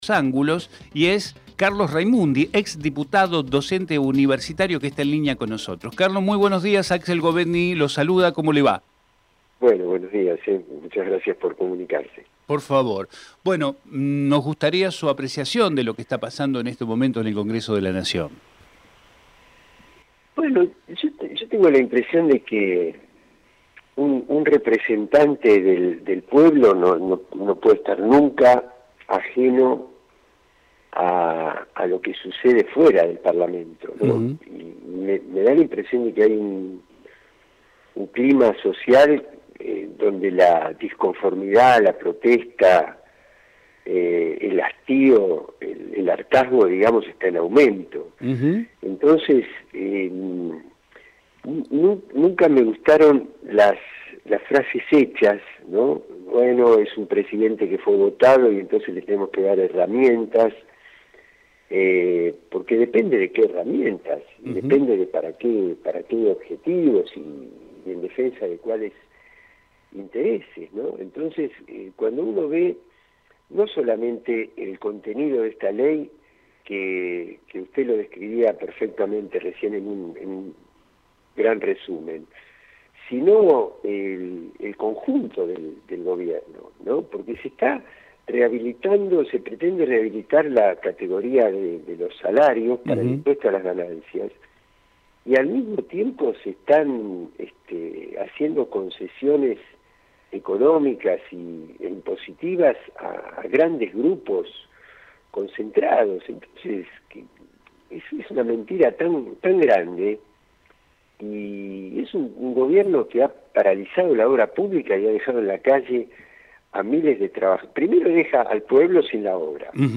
TERRITORIO SUR - CARLOS RAIMUNDI Texto de la nota: Compartimos con ustedes la entrevista realizada en Territorio Sur a Carlos Raimundi, ex Diputado y Docente Universitario Archivo de audio: TERRITORIO SUR - CARLOS RAIMUNDI Programa: Territorio Sur